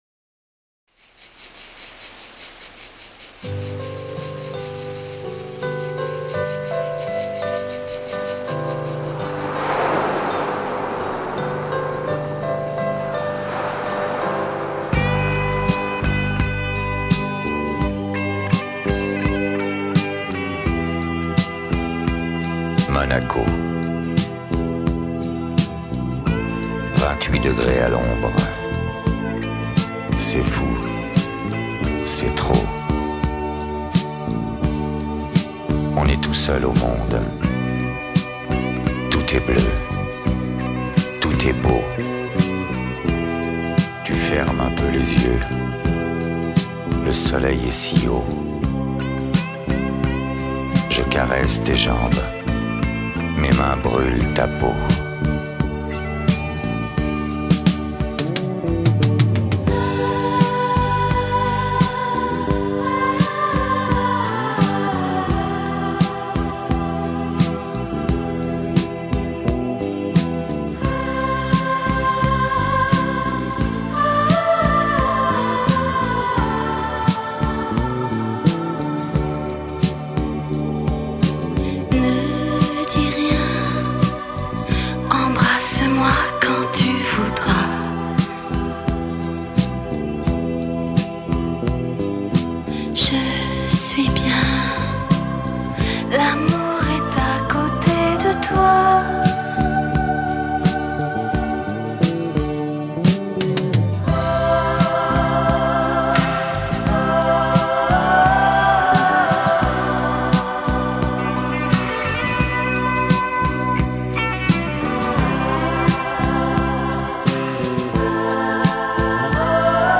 Genre: Pop, French, Retro